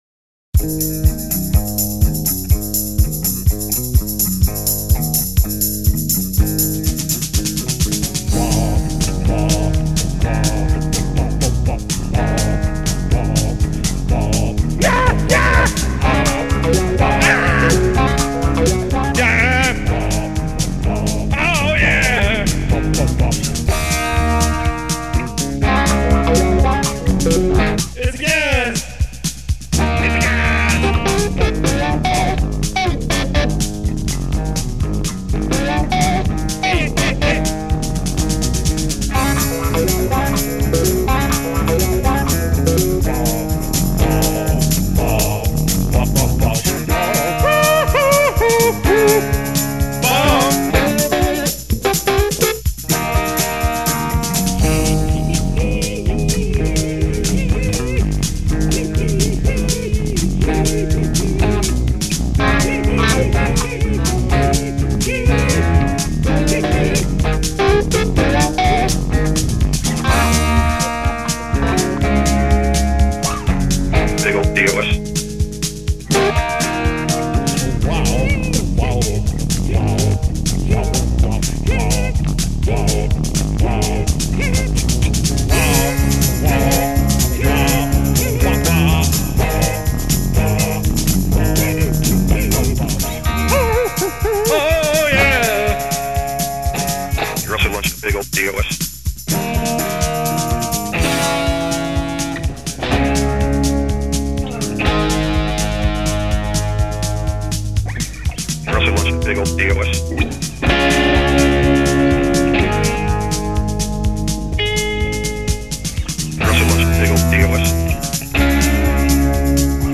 Rhythmically, it works kind of nicely.
Tech note: The Internet saw fit to put a millisecond drop-out into the song.
Rock.